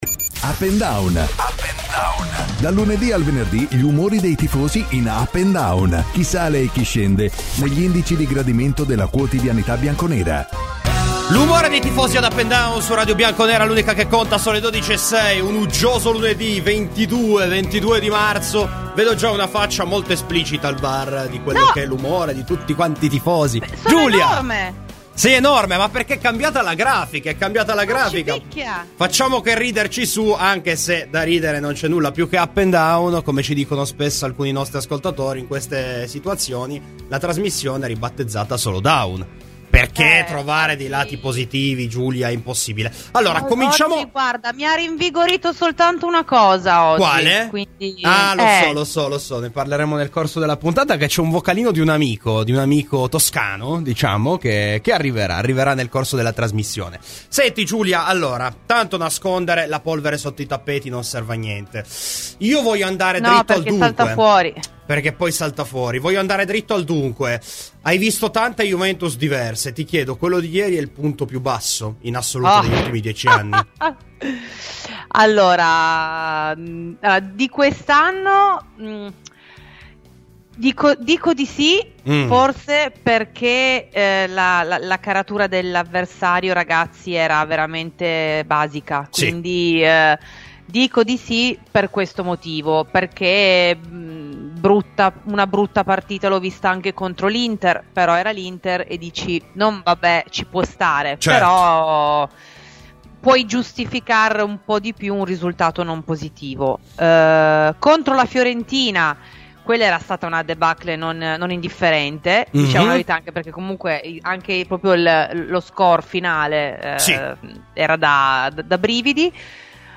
Clicca sul podcast in calce per ascoltare la trasmissione integrale.